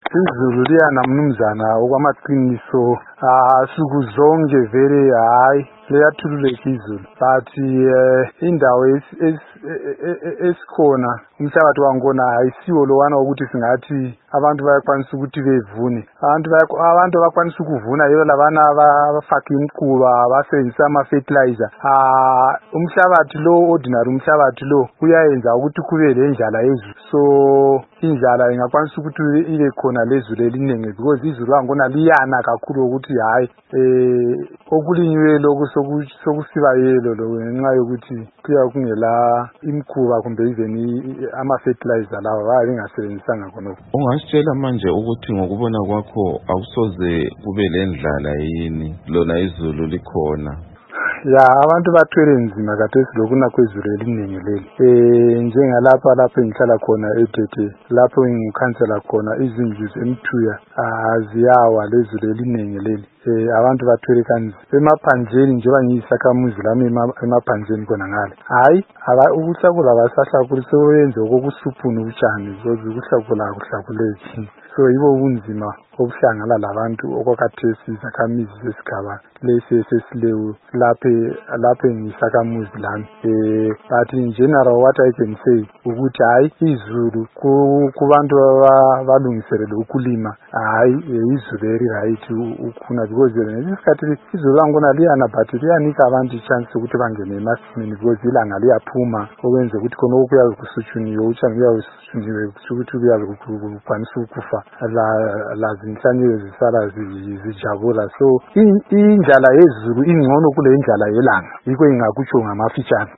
Siphinde saxoxa lokhansila weDete esigabeni se Matabeleland North, uMnu. Stanley Torima ukuze sizwe ukuthi umumo wezulu unjani kulesi sigaba.
Ingxoxo loMnu. Stanley Torima